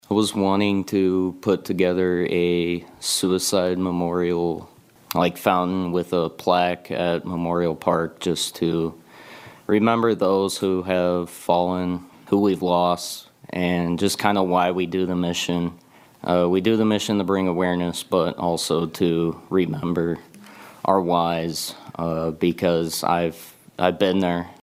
At Tuesday night’s Manteno Village Board Meeting